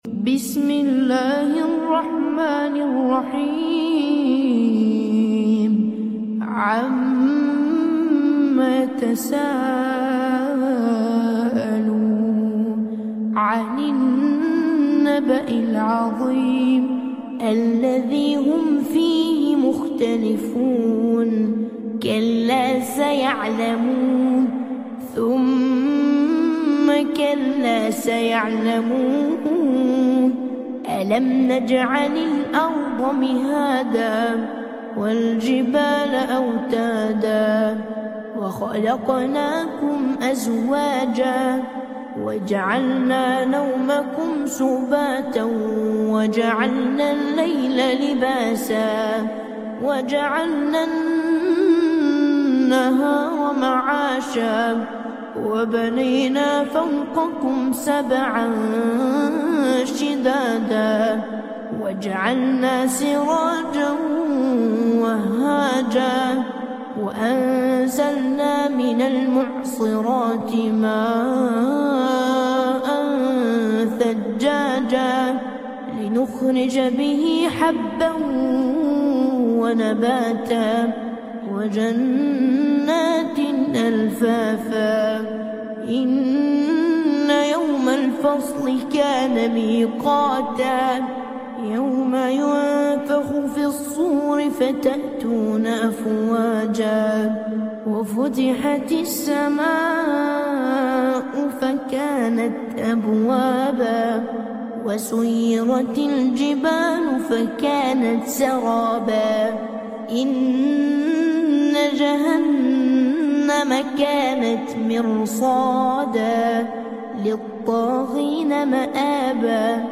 Relaxing and Peaceful Quran Recitation